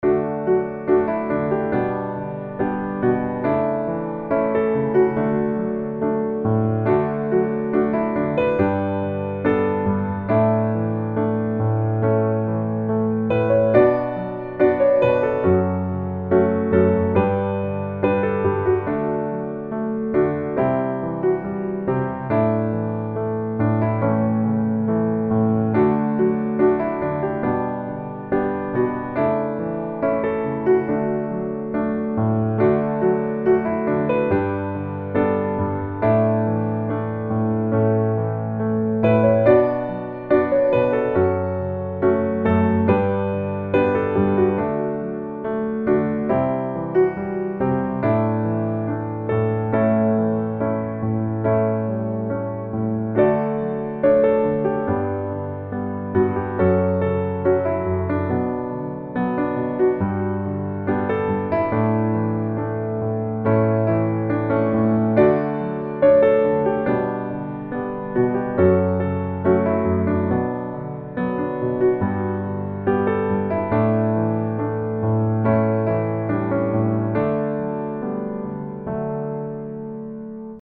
D Major